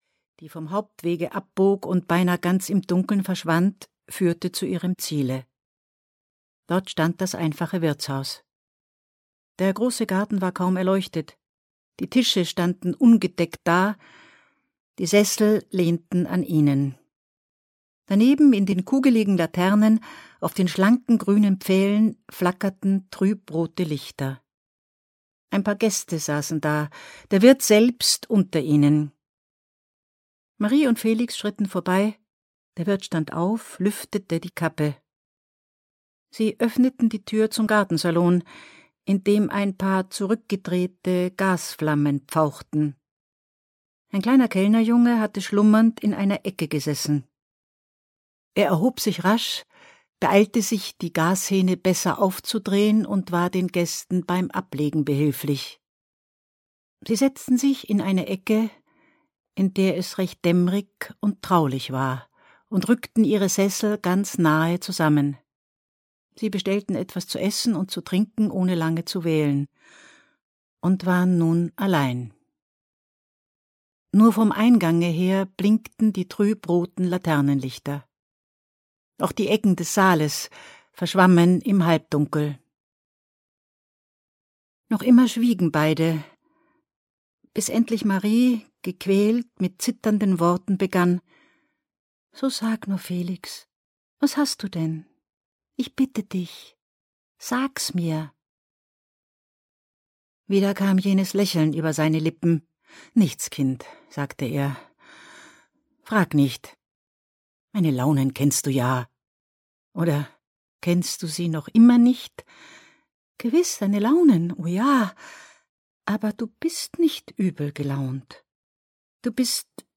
Sterben - Arthur Schnitzler - Hörbuch